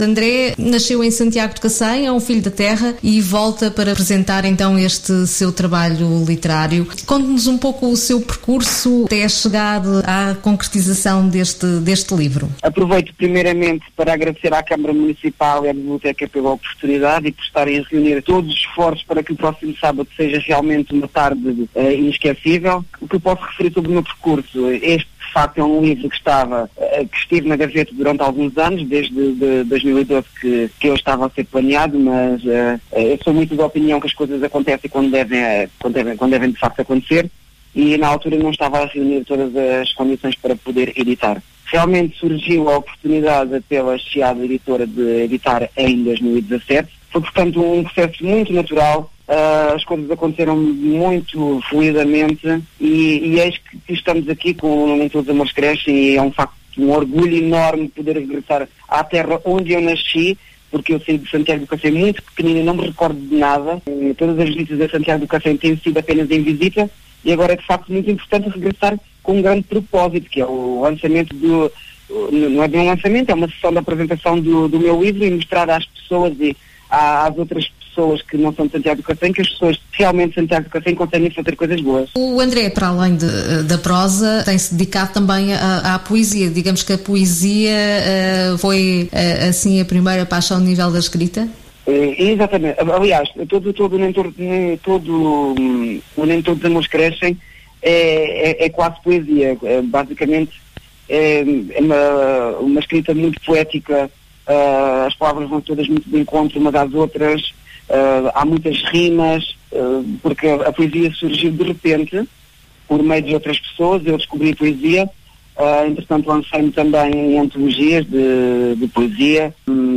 entrevista
no programa de rádio De Porta Aberta (Miróbriga Rádio 102.7FM)